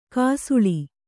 ♪ kāsuḷi